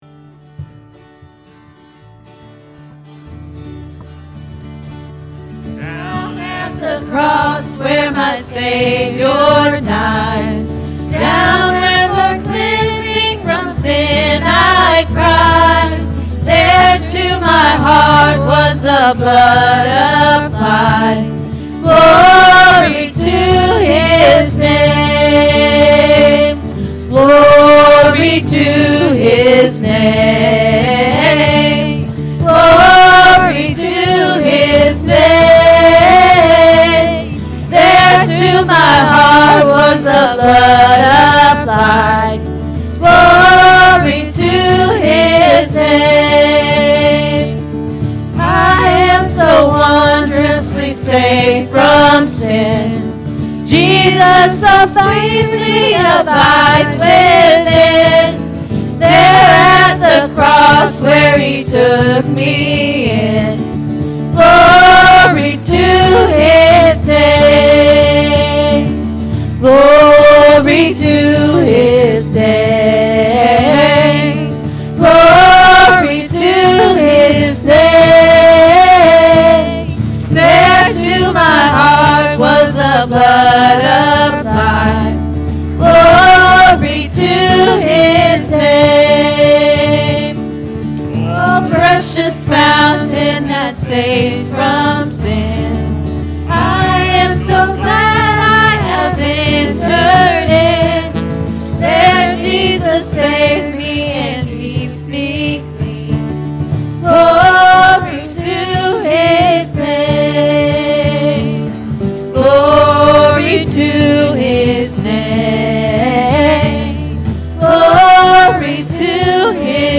Be Wise, Tomorrow May Never Come-A.M. Service – Anna First Church of the Nazarene